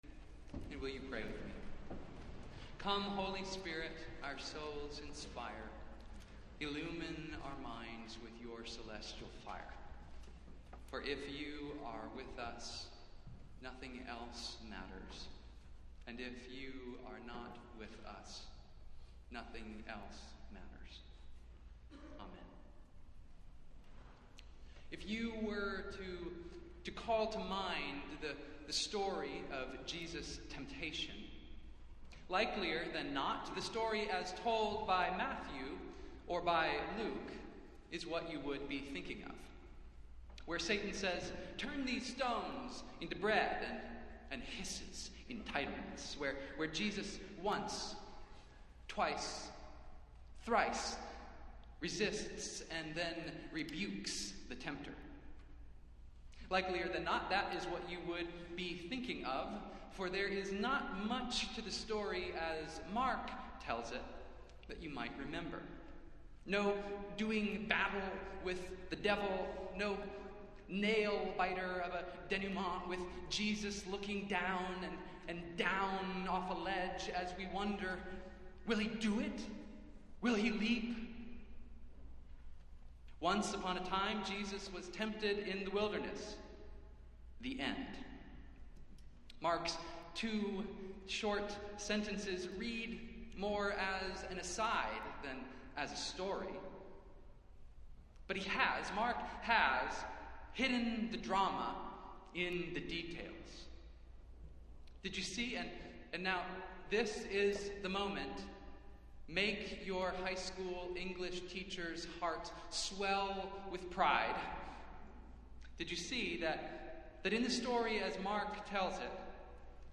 Festival Worship - First Sunday in Lent | Old South Church in Boston, MA
Festival Worship - First Sunday in Lent